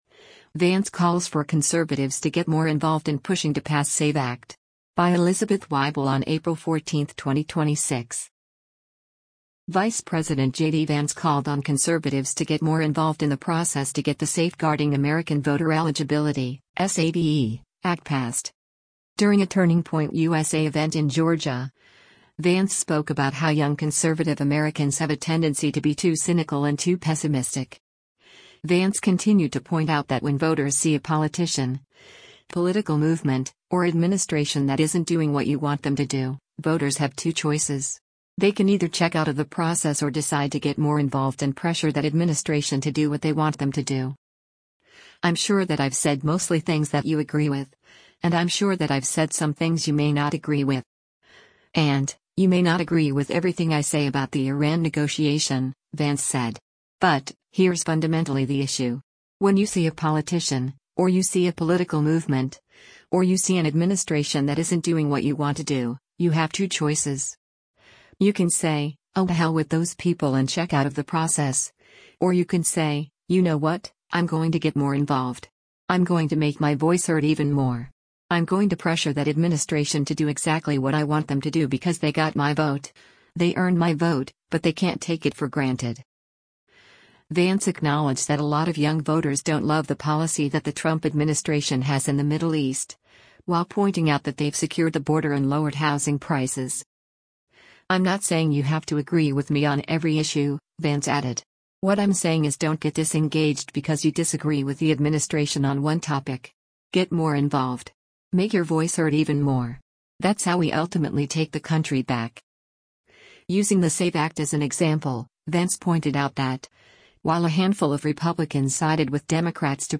U.S. Vice President JD Vance takes the stage during a Turning Point USA event at Akins For